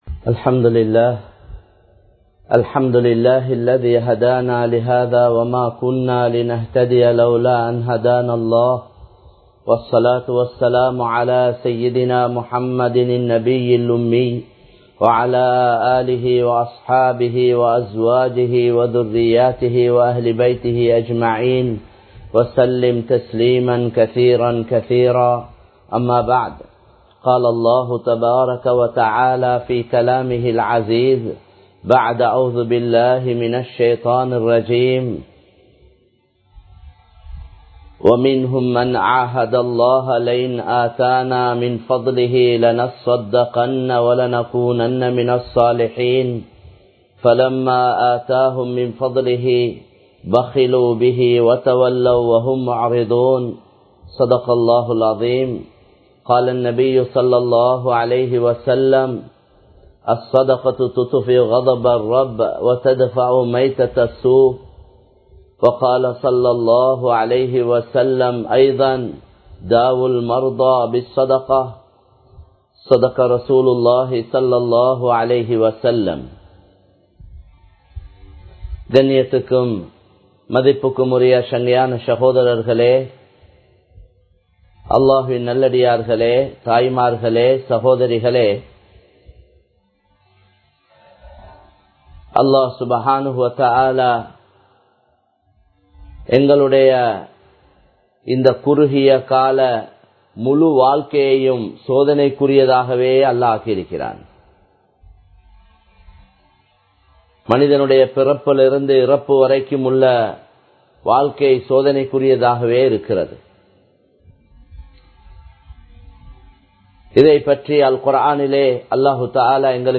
நிலமைகளை மாற்றக் கூடியவன் அல்லாஹ் | Audio Bayans | All Ceylon Muslim Youth Community | Addalaichenai
Live Stream